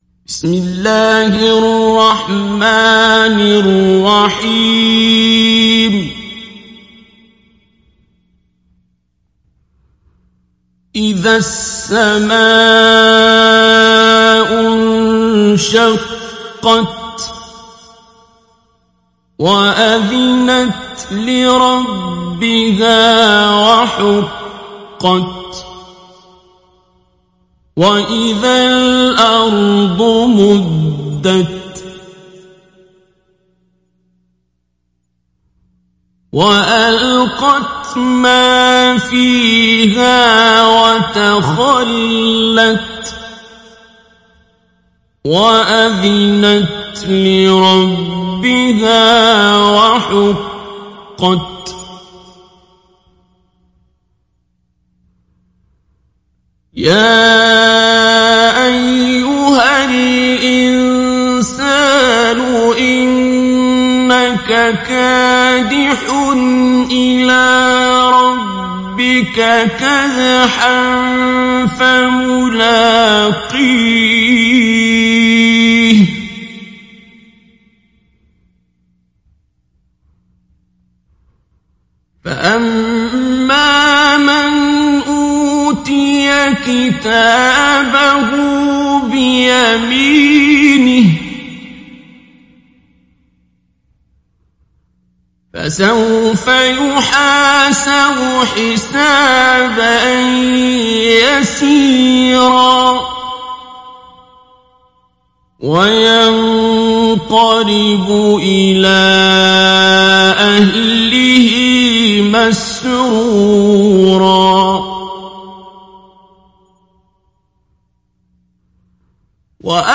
تحميل سورة الانشقاق mp3 بصوت عبد الباسط عبد الصمد مجود برواية حفص عن عاصم, تحميل استماع القرآن الكريم على الجوال mp3 كاملا بروابط مباشرة وسريعة
تحميل سورة الانشقاق عبد الباسط عبد الصمد مجود